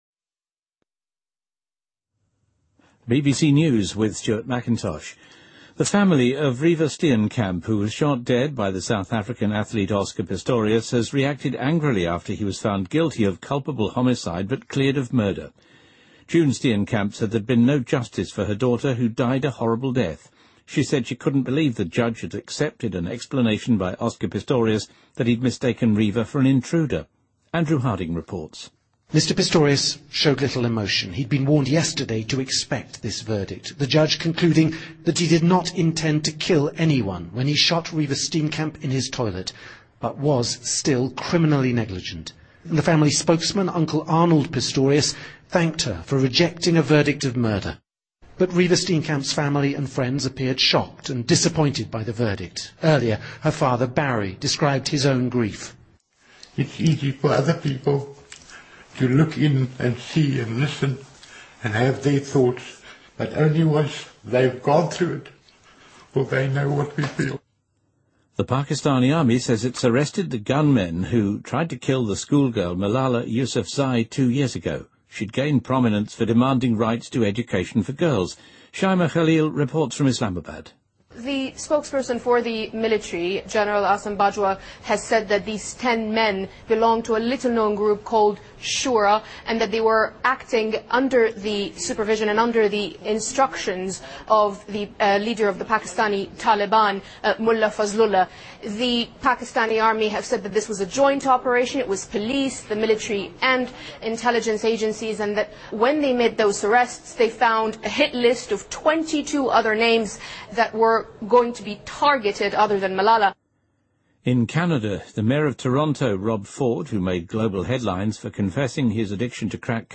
BBC news,美国对俄罗斯实施了新一轮的制裁